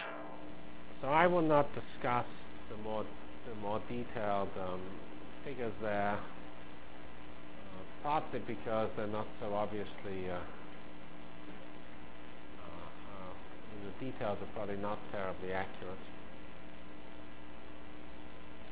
Delivered Lectures of CPS615 Basic Simulation Track for Computational Science